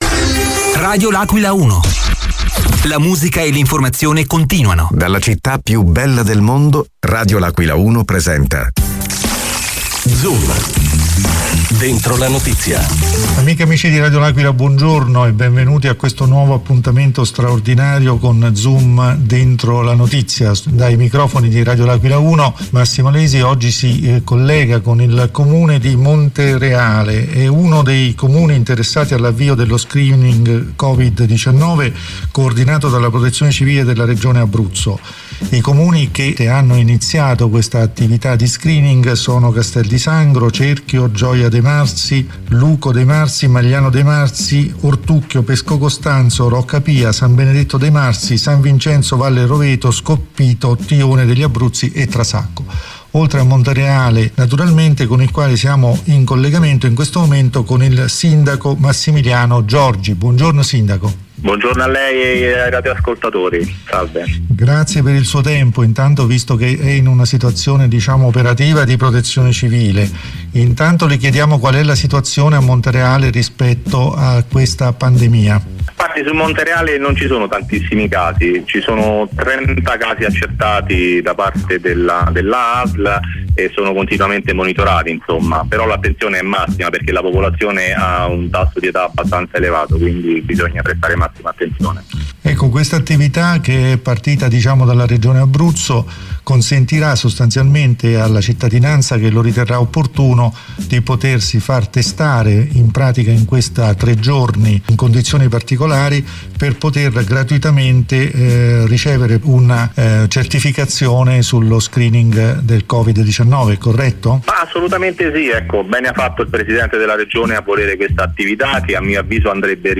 L’AQUILA – Con Massimilino Giorgi, Sindaco del Comune di Montereale, parla della situazione dello screening volontario per l’individuazione di eventuali casi di contagio del Covid-19. Per Giorgi la situazione a Montereale è sotto controllo, ci sono indubbiamente dei problemi ma vanno affrontati senza farci prendere dello scoramento.